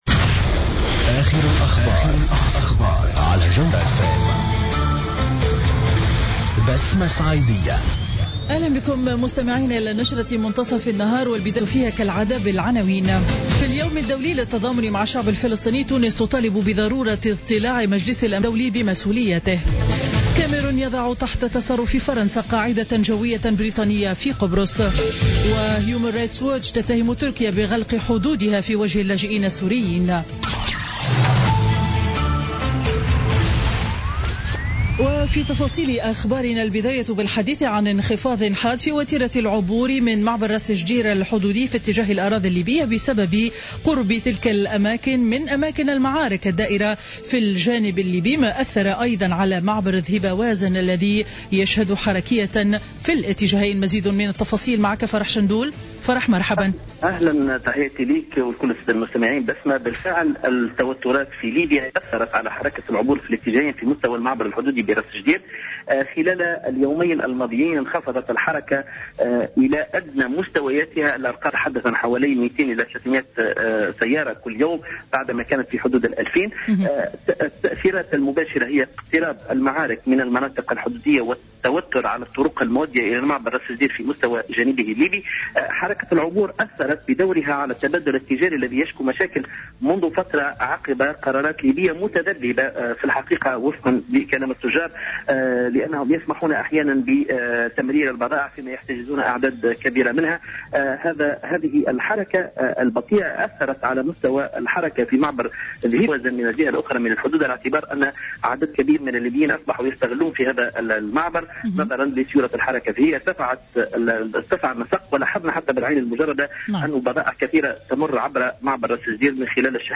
نشرة أخبار منتصف النهار ليوم الإثنين 23 نوفمبر 2015